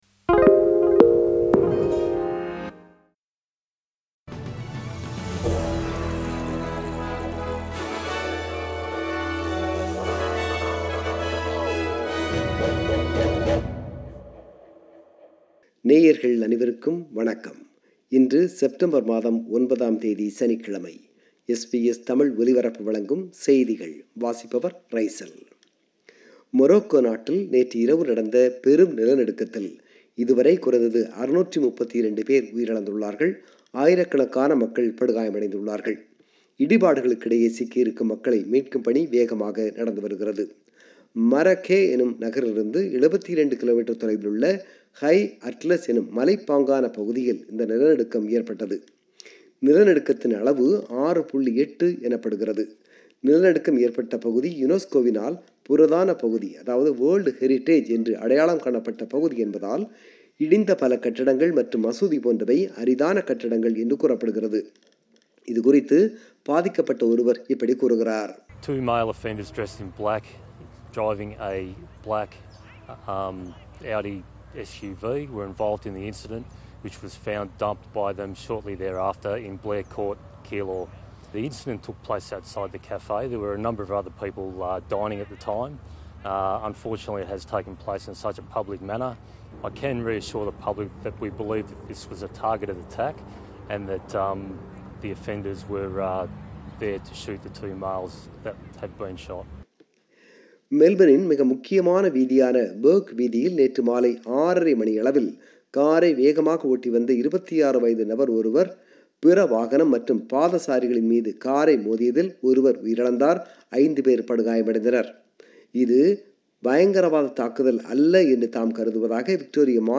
செய்திகள்